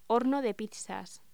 Locución: Horno de pizzas
voz